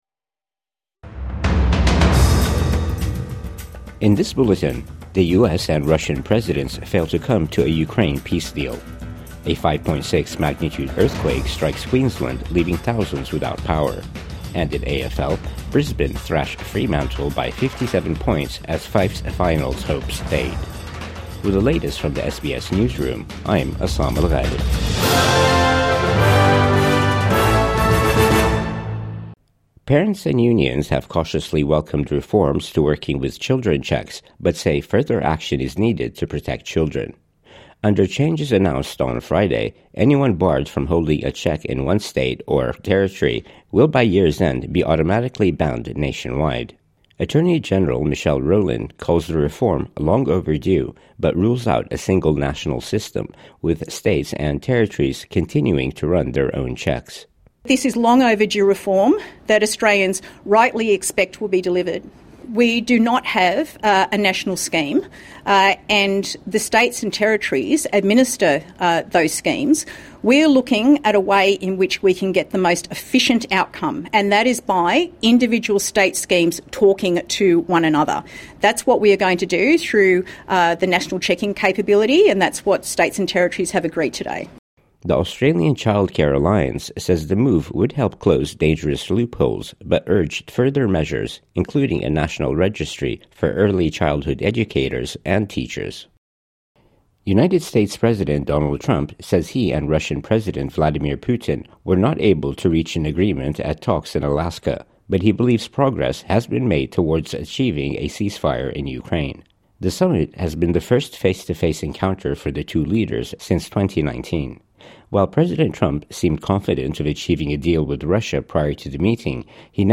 Midday News Bulletin 16 August 2025